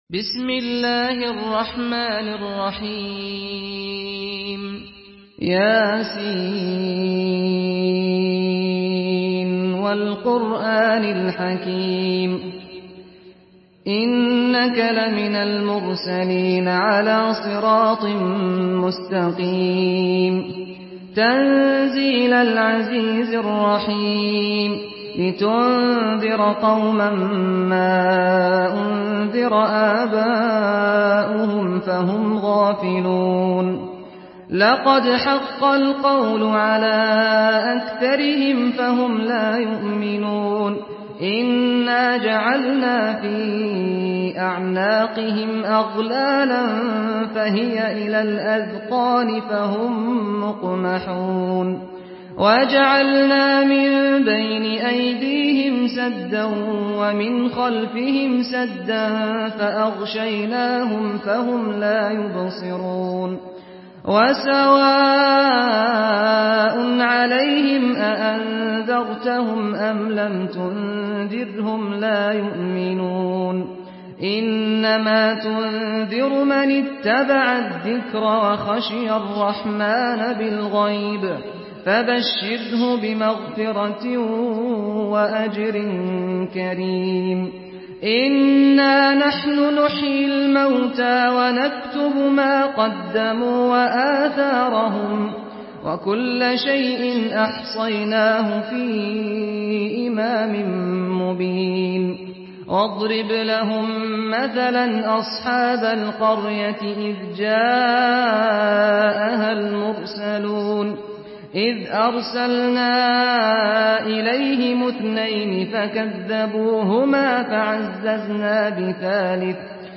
Surah Yasin MP3 by Saad Al-Ghamdi in Hafs An Asim narration.
Murattal Hafs An Asim